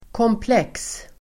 Uttal: [kåmpl'ek:s]